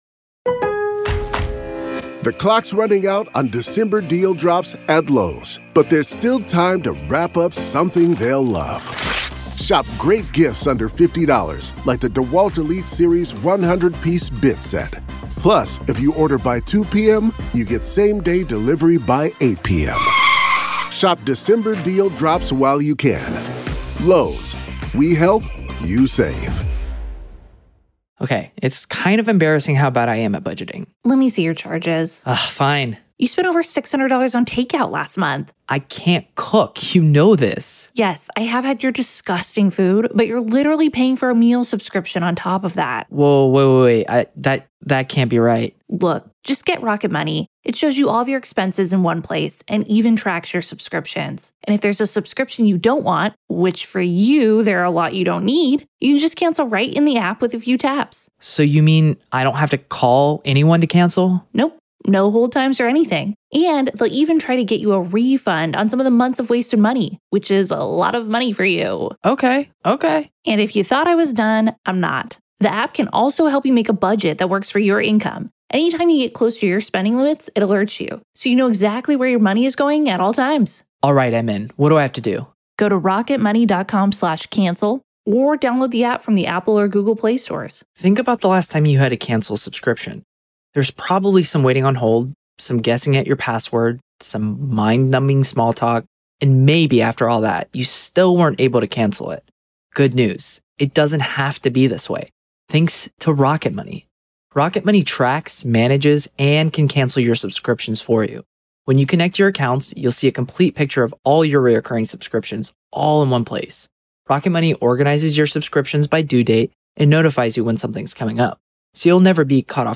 COURT AUDIO: MA v. Karen Read Murder Retrial - Motions Hearing PART 1